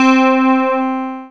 BELL 2.wav